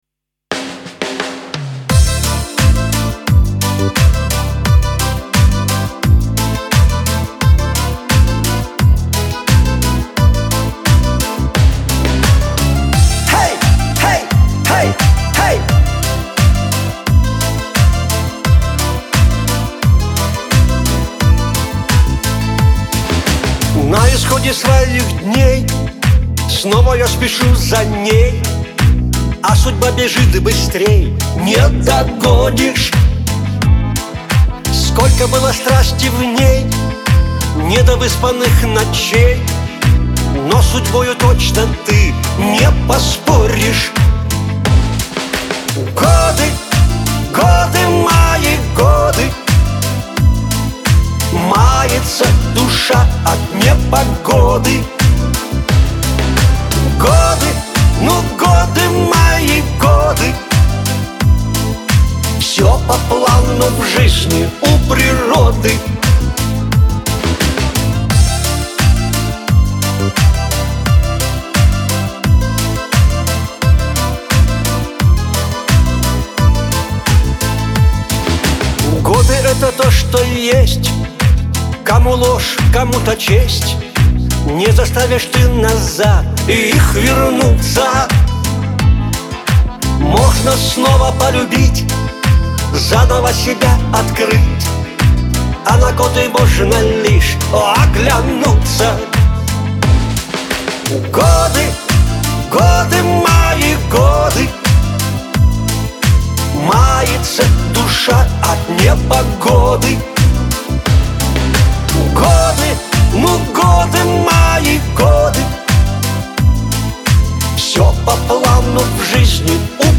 Шансон , диско
грусть